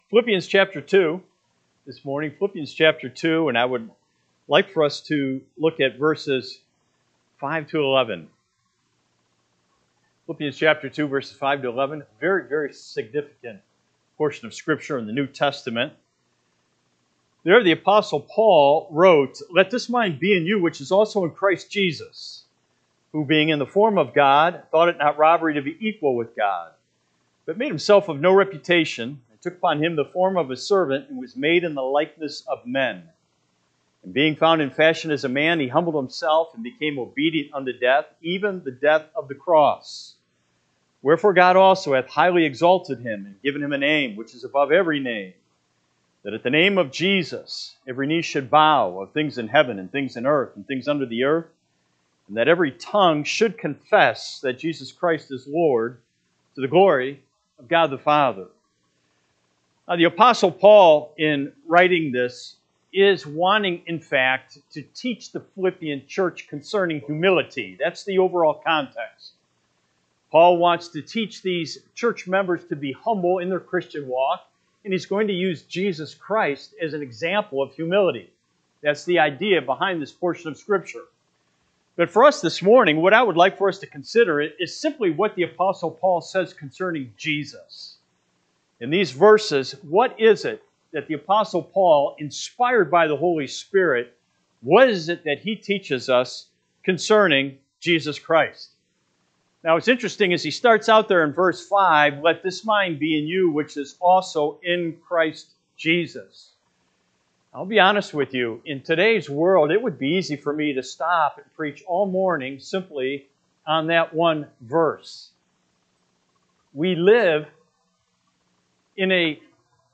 September 21, 2025 am Service Philippians 2:5-11 (KJB) 5 Let this mind be in you, which was also in Christ Jesus: 6 Who, being in the form of God, thought it not robbery to be equal with …